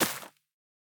Minecraft Version Minecraft Version snapshot Latest Release | Latest Snapshot snapshot / assets / minecraft / sounds / block / azalea / break5.ogg Compare With Compare With Latest Release | Latest Snapshot
break5.ogg